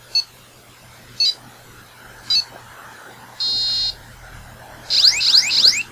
Sehr viel praktischer ist ein Piezo-Schallwandler.
Wer mag, kann sich die Pieptöne anhören: